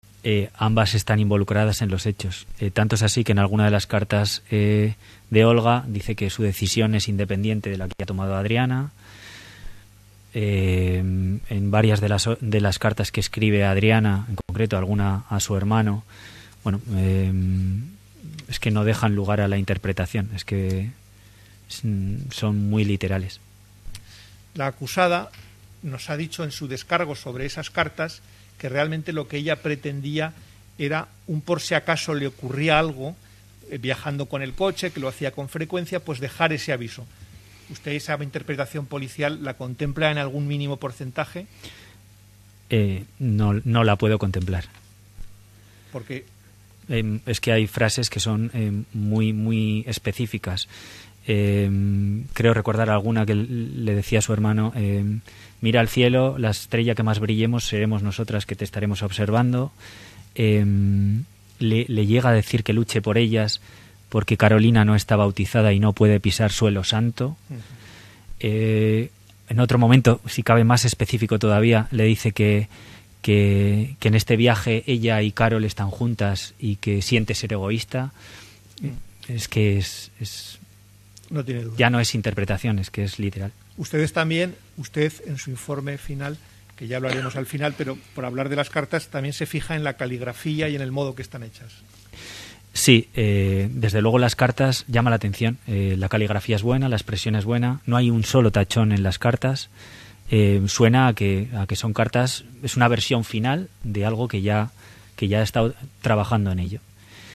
JUICIO-INVESTIGADOR-CARTAS_.mp3